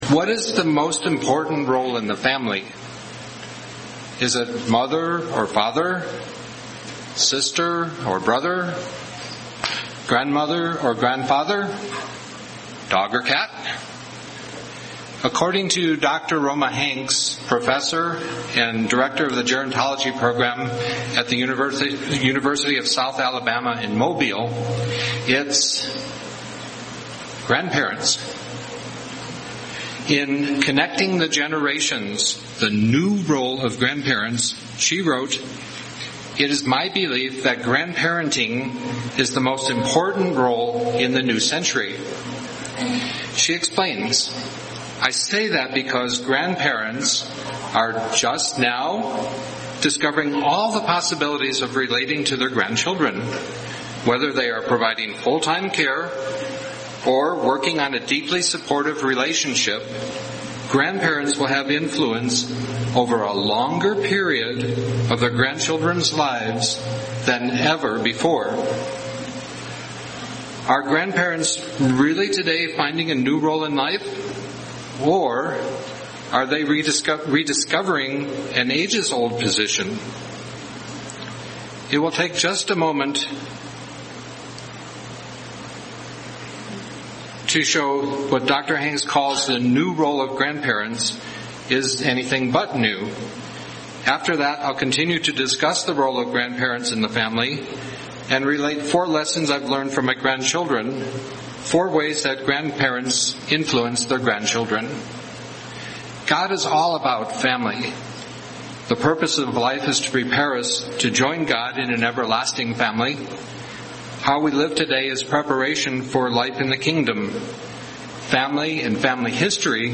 Given in Albuquerque, NM Phoenix East, AZ
Print Four ways that grandparents can influence their grandchildren UCG Sermon Studying the bible?